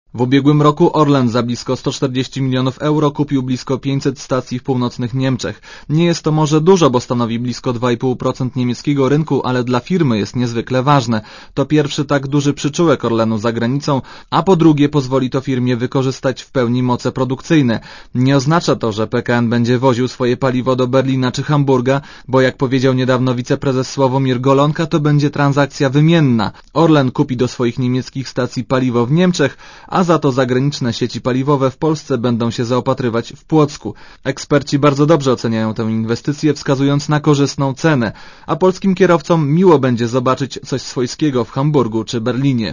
Komentarz audio (170Kb)